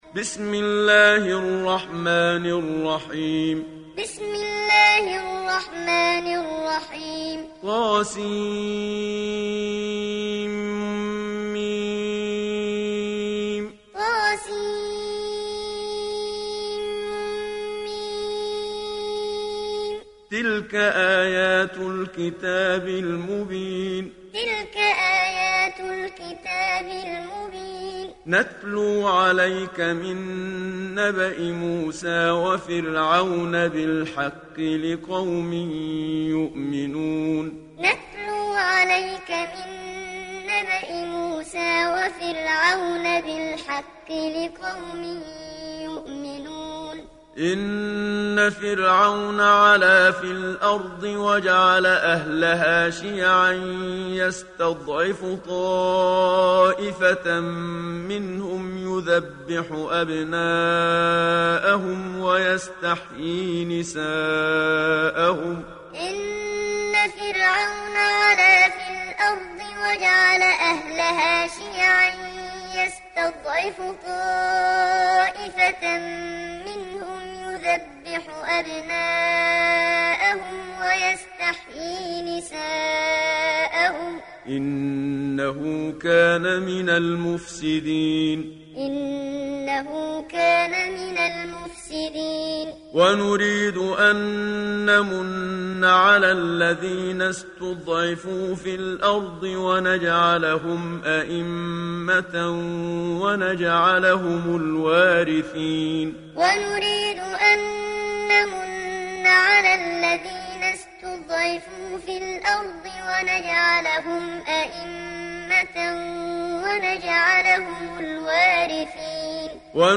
تحميل سورة القصص mp3 بصوت محمد صديق المنشاوي معلم برواية حفص عن عاصم, تحميل استماع القرآن الكريم على الجوال mp3 كاملا بروابط مباشرة وسريعة
تحميل سورة القصص محمد صديق المنشاوي معلم